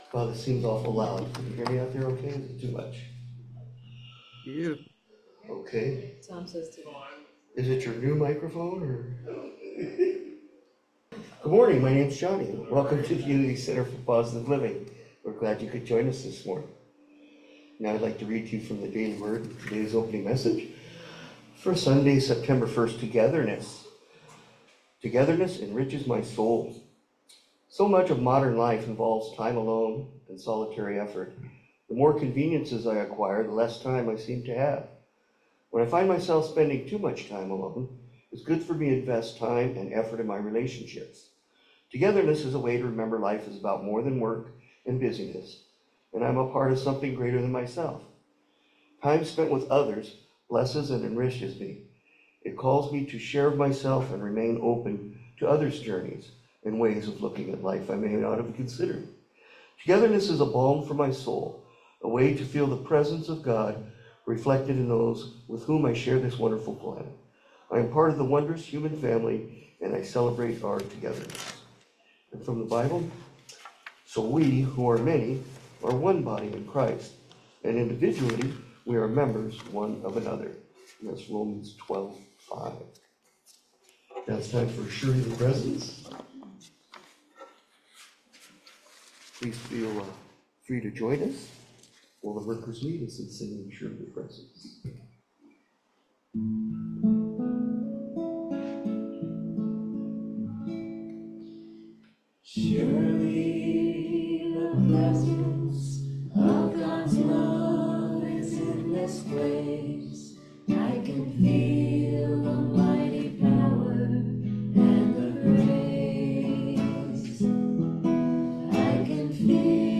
September 1, 2024 Service